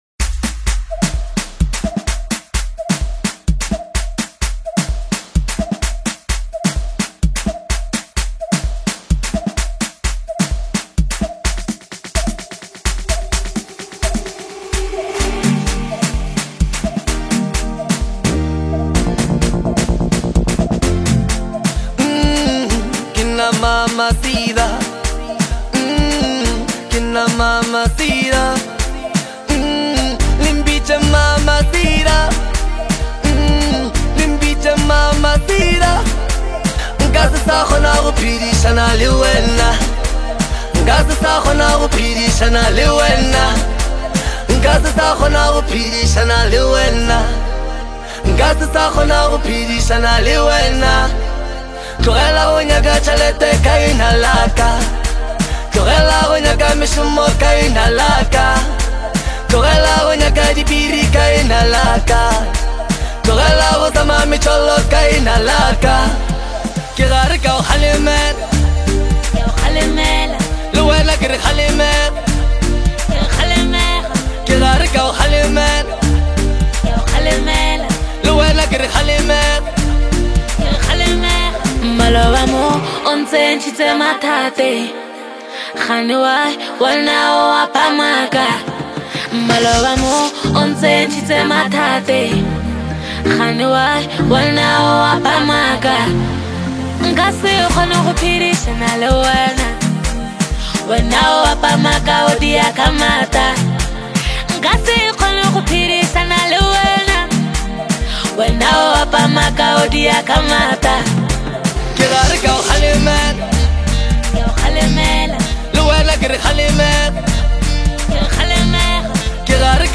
It’s confident, direct, and unapologetically honest.
Vocals, Production, and Chemistry